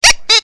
clock07.ogg